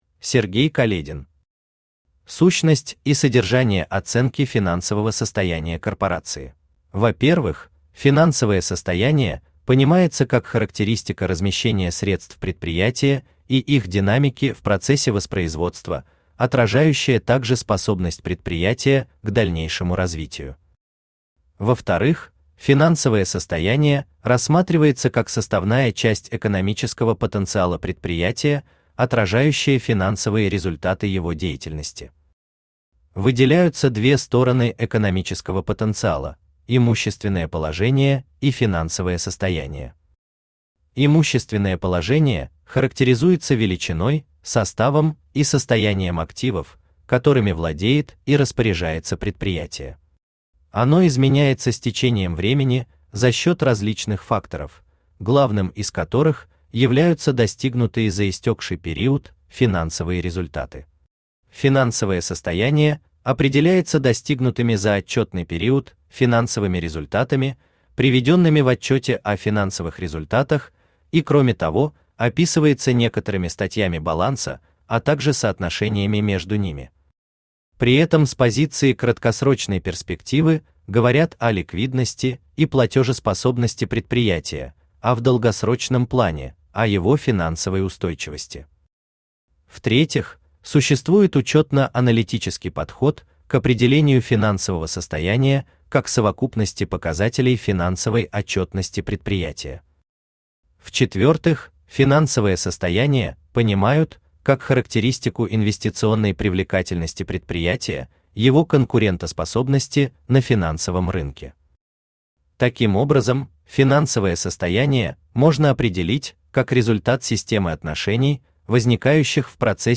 Аудиокнига Сущность и содержание оценки финансового состояния корпорации | Библиотека аудиокниг
Aудиокнига Сущность и содержание оценки финансового состояния корпорации Автор Сергей Каледин Читает аудиокнигу Авточтец ЛитРес.